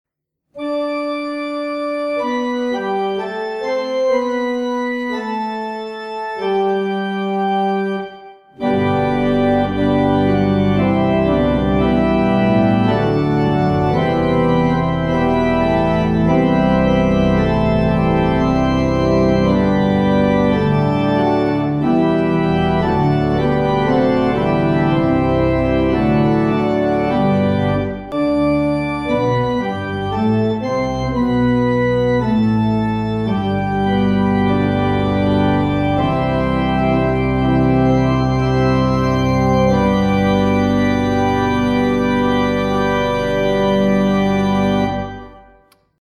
Organ Accompaniment